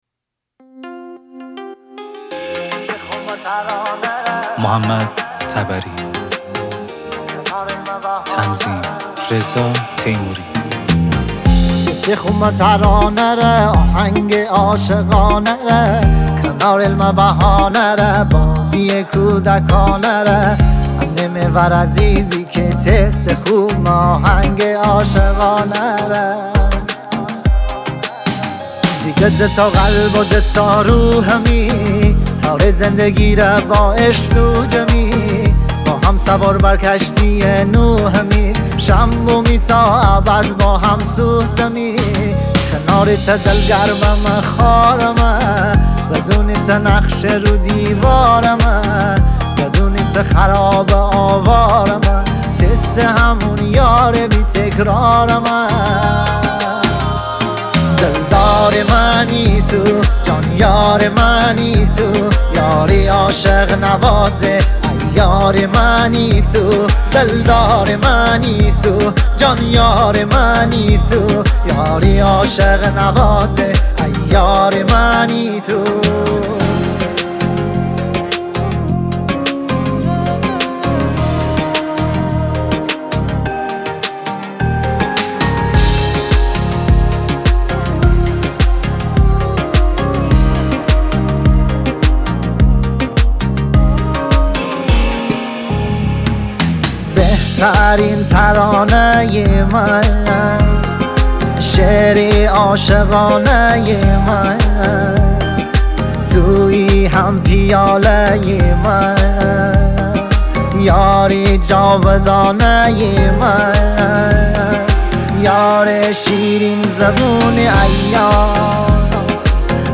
اهنگ مازندرانی